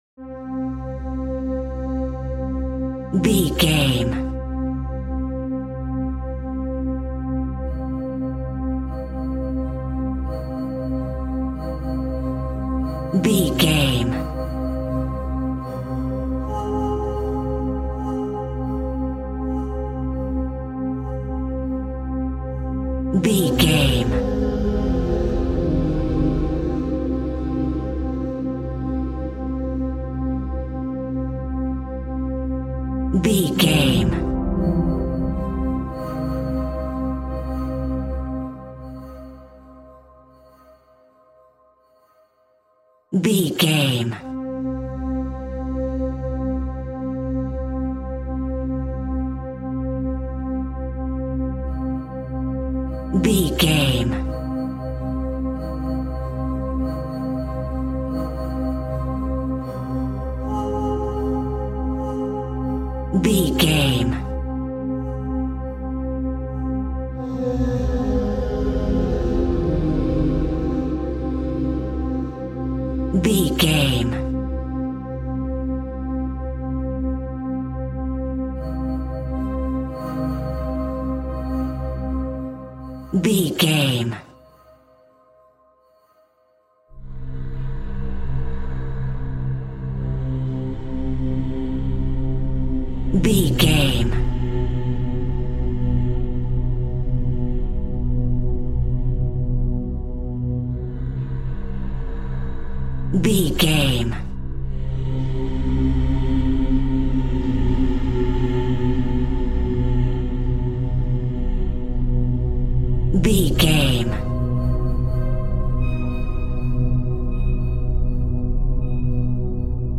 Thriller Film Music.
Atonal
tension
ominous
eerie
suspenseful
Synth Pads
Synth Strings
synth bass